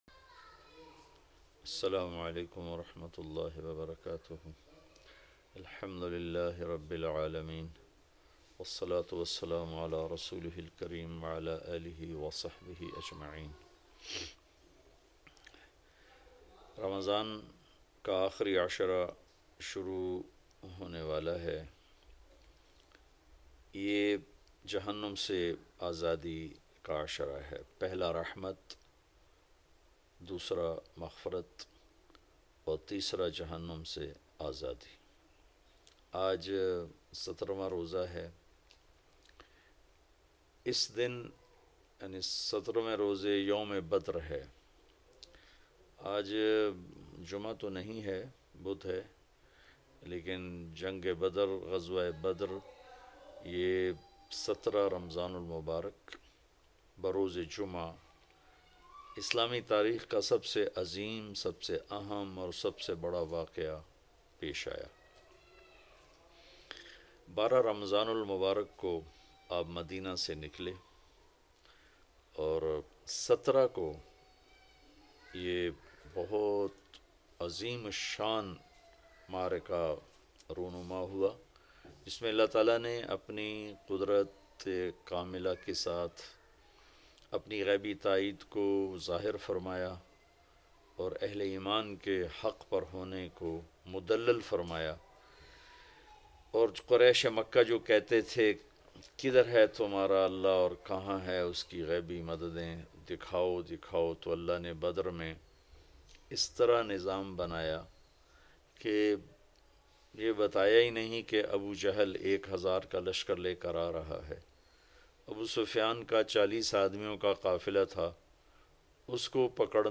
Ghazwa-e-Badr Aur Wafat Ami Hazrat Ayesha R.A, Listen or play online 17 Ramzan ul Mubarik Special Bayan by Maulana Tariq Jameel.